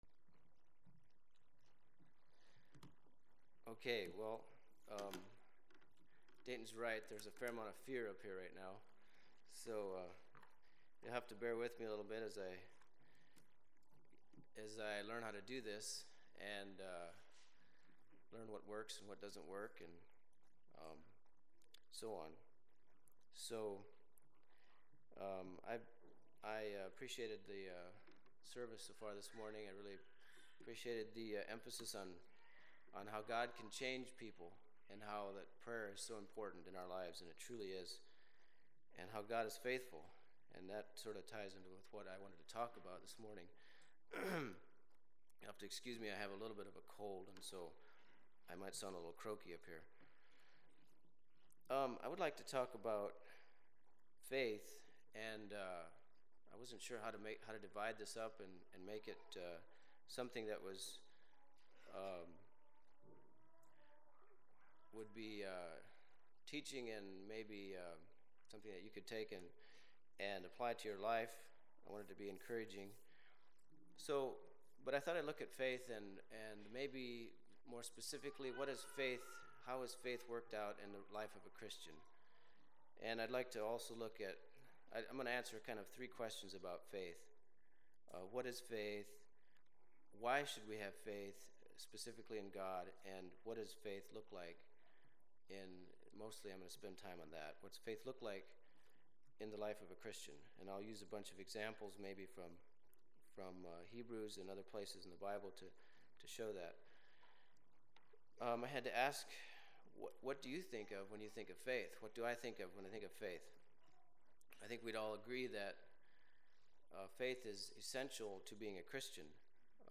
This is a program that Maranatha Bible School gave at our church on their tour this spring.